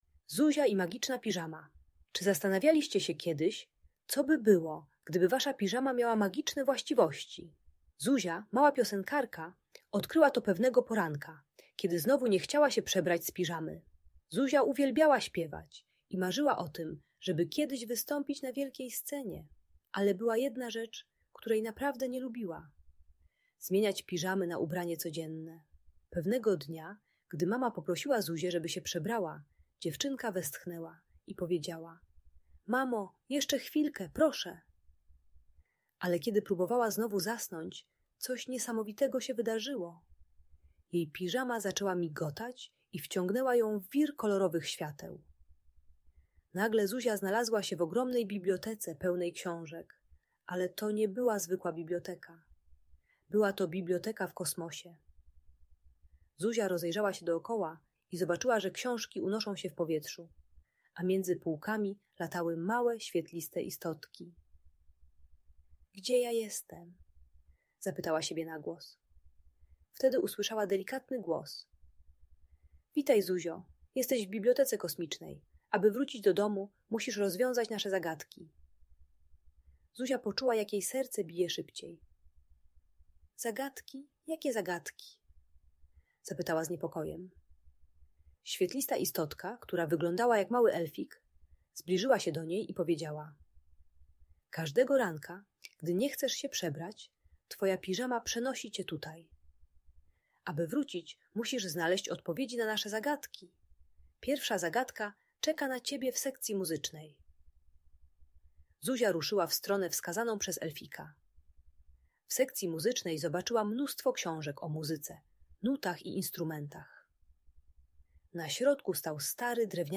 Zuzia i Magiczna Piżama: Niezwykła Opowieść - Audiobajka